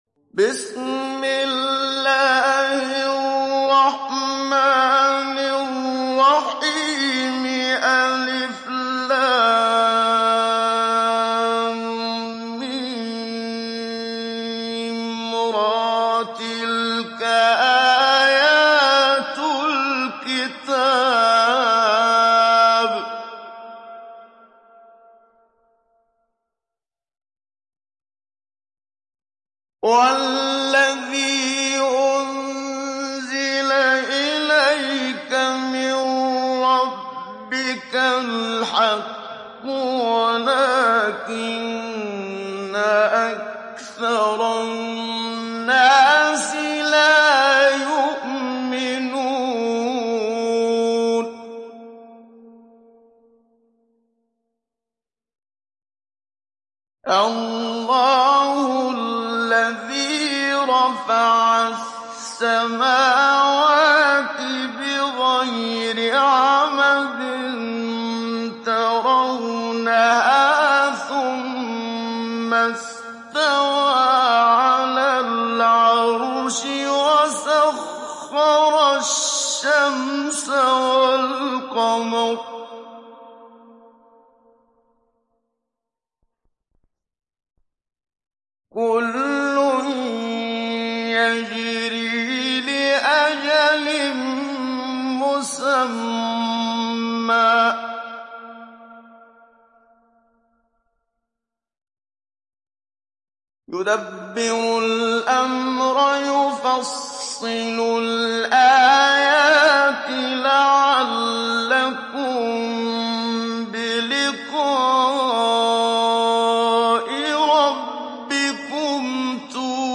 دانلود سوره الرعد محمد صديق المنشاوي مجود